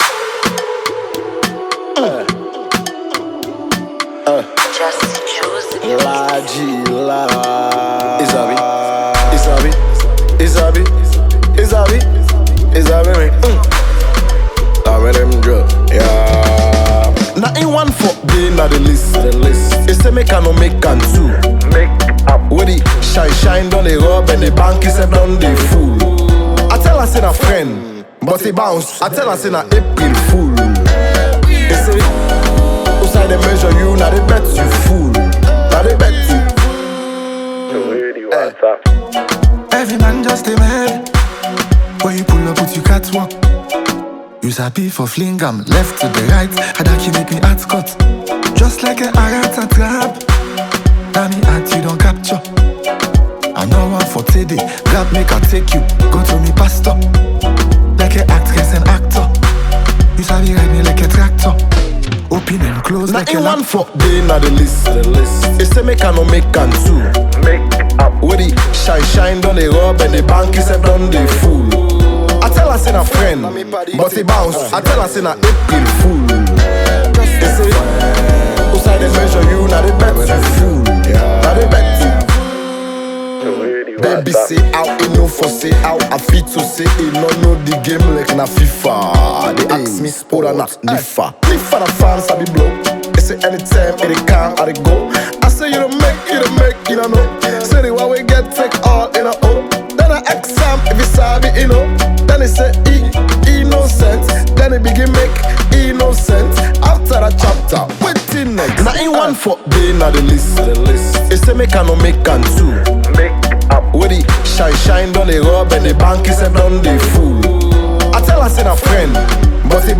Sierra Leonean freestyle singer
melodious Afrobeat joint